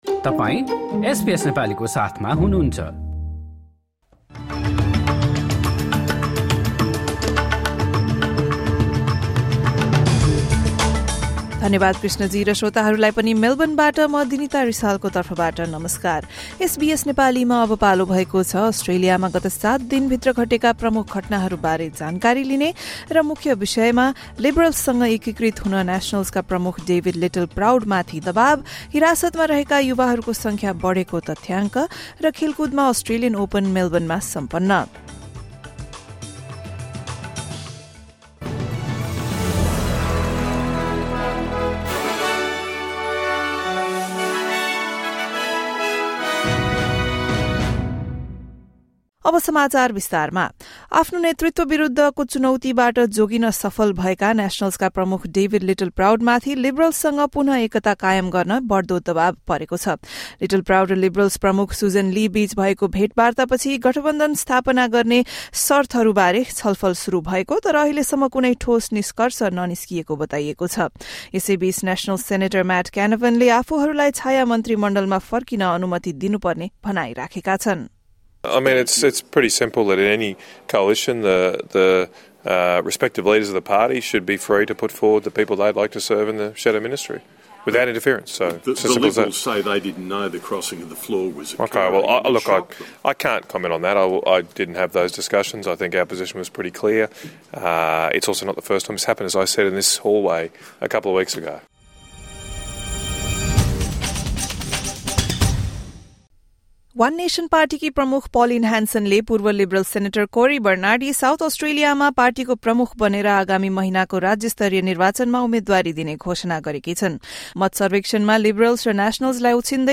रिजर्भ ब्याङ्कद्वारा ब्याज दरमा वृद्धि, लिबरलसँग एकता गर्न नेश्नल्सका प्रमुख डेभिड लिटलप्राउड माथि दबाब बढ्दै र मेलबर्नमा अस्ट्रेलियन ओपन सम्पन्न लगायत एक हप्ता यताका प्रमुख घटनाहरू बारे एसबीएस नेपालीबाट समाचार सुन्नुहोस्।